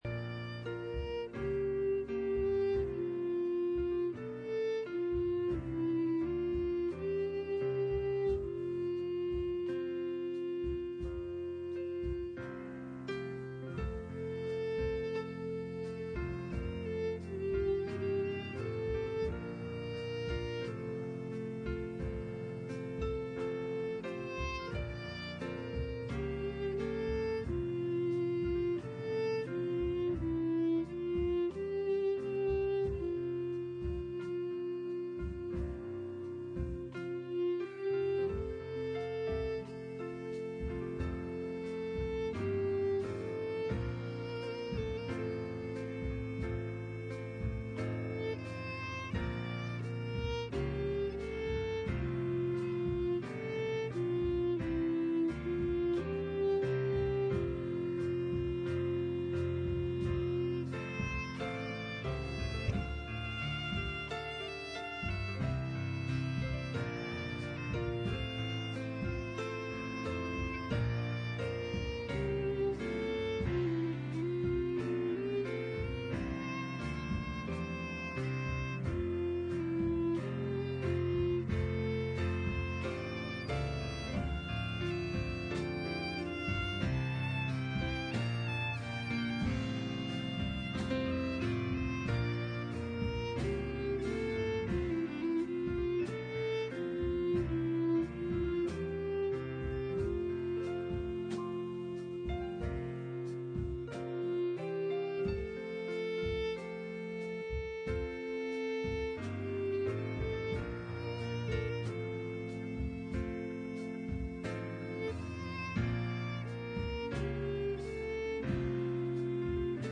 Guest Minister Service Type: Sunday Morning %todo_render% « Study On Hebrews 10